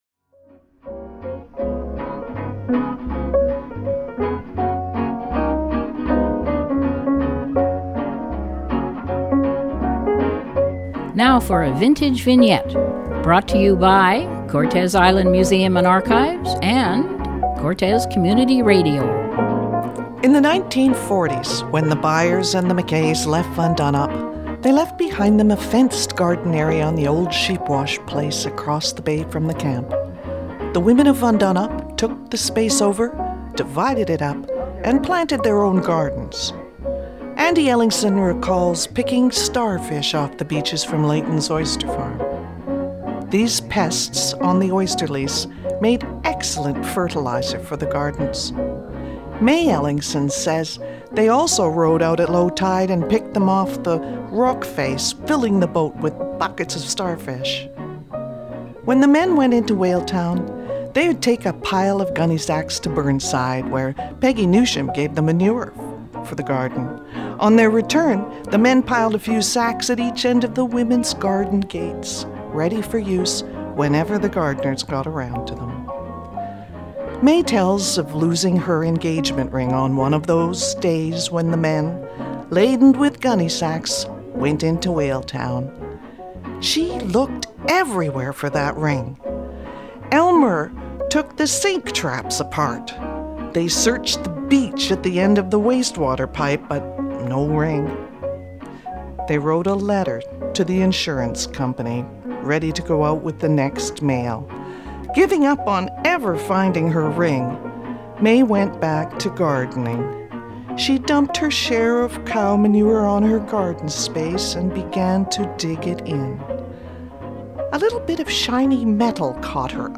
These brief episodes feature dramatized voices from the past with archive recordings of music from the “Old Timers”, a dance band that played locally for several decades.